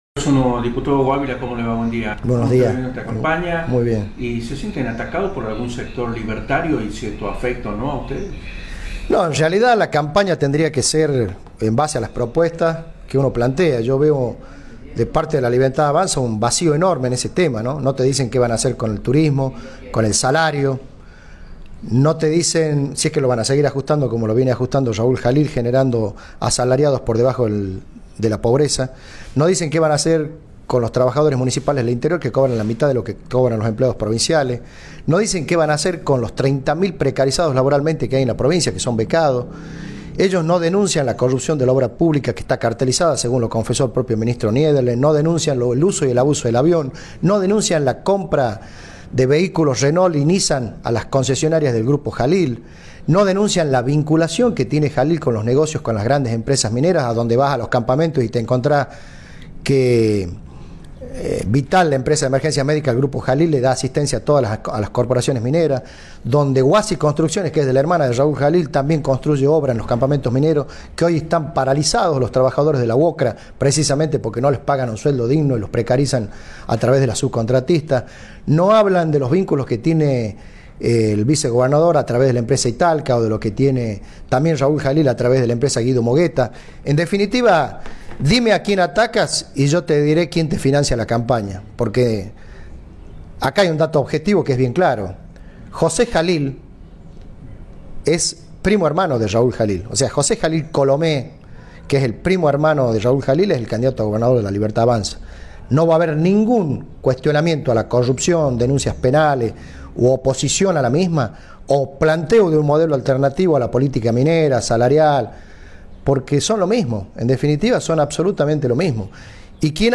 Entrevistas CityRadio CiTy Entrevistas